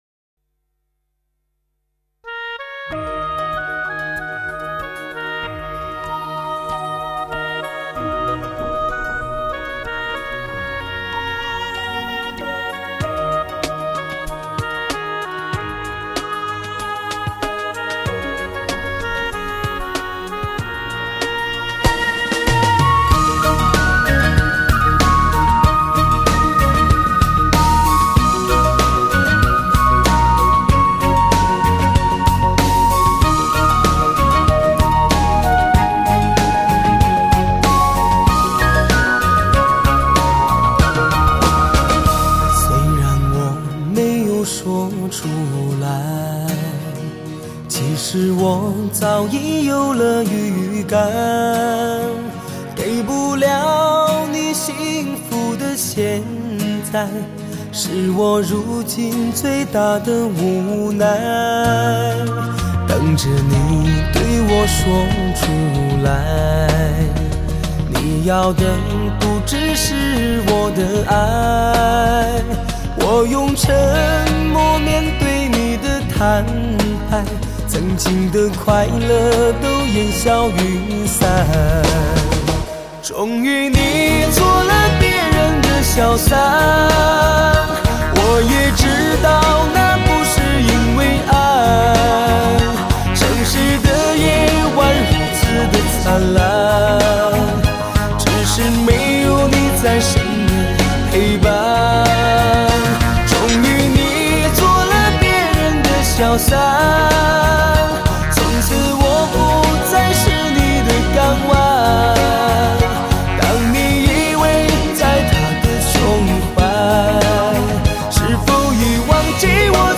幽怨的旋律，最感人肺腑之情。。。